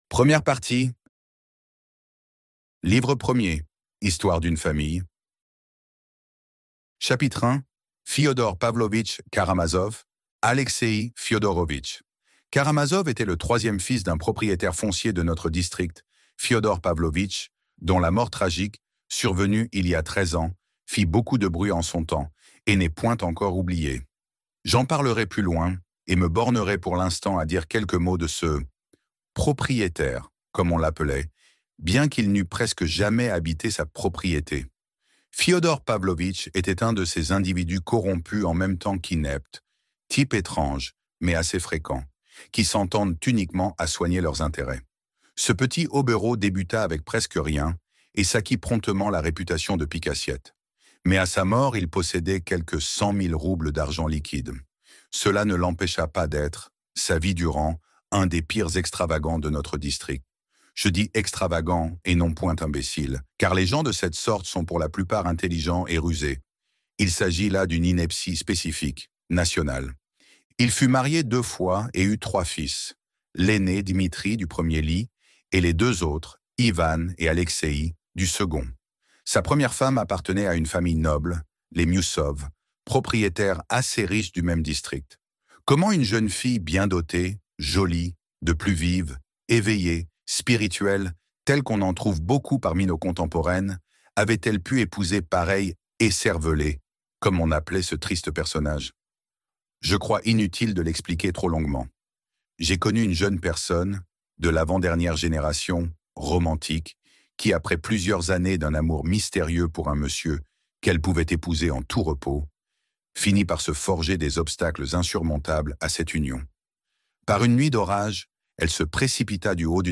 Les frères Karamazov - Livre Audio